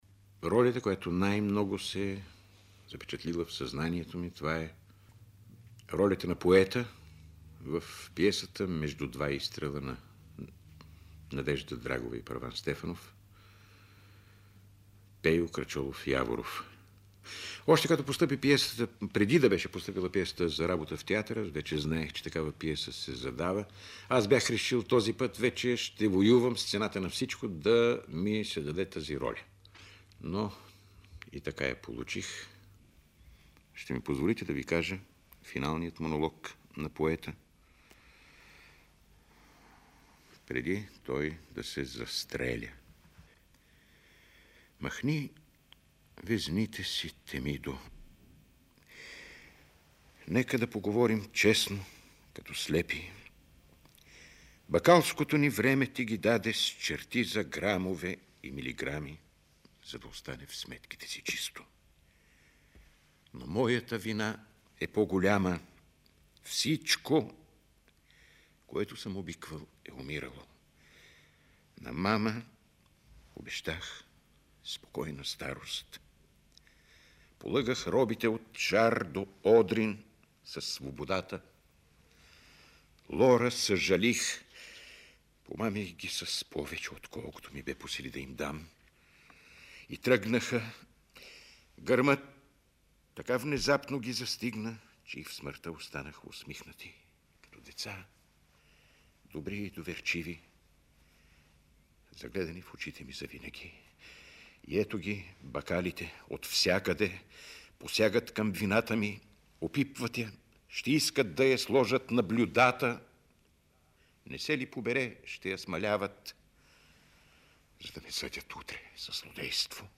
В запис от 1975 година той откроява именно тази роля като „запечатана в съзнанието“ му и изпълнява финалния монолог на Поета: